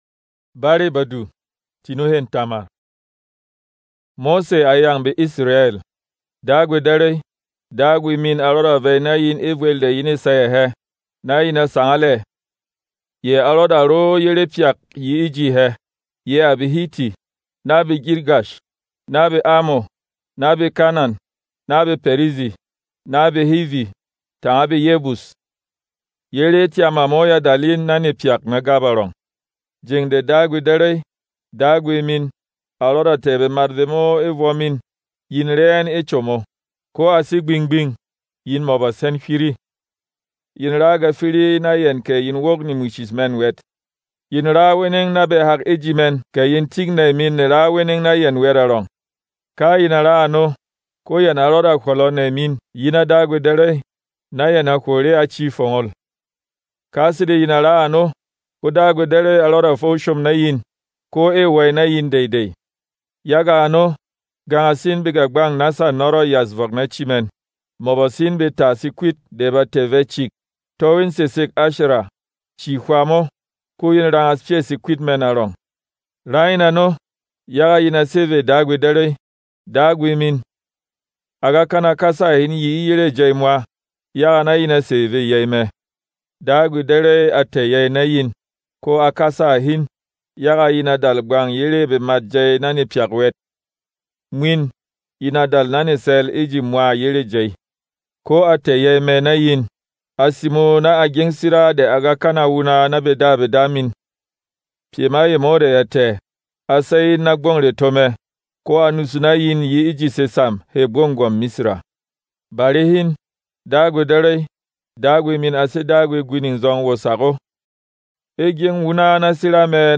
Non-Drama